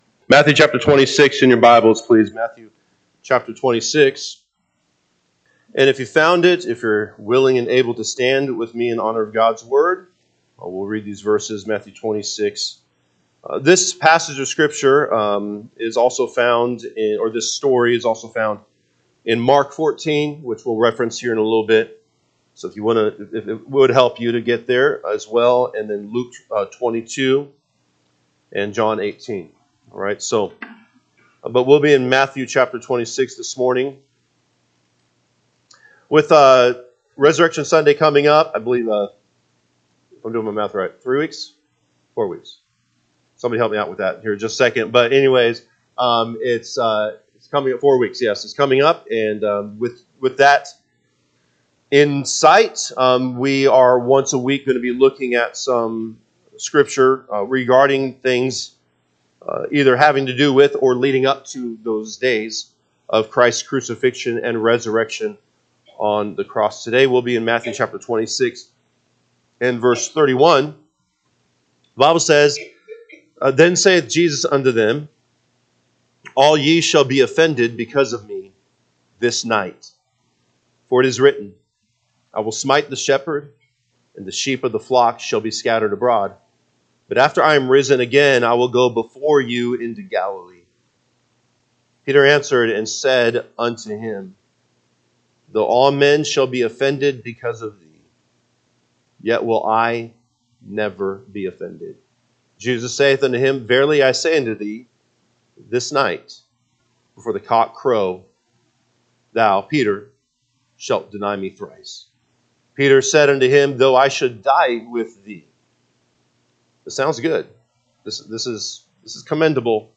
March 30, 2025 am Service Matthew 26:31-35 (KJB) 31 Then saith Jesus unto them, All ye shall be offended because of me this night: for it is written, I will smite the shepherd, and the sheep o…